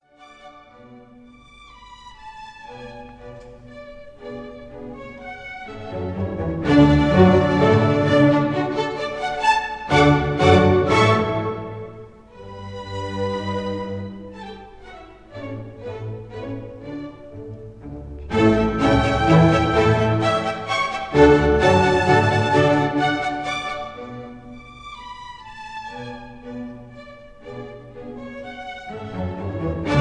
in D minor K 385
conductor
Recorded in the Kingsway Hall, London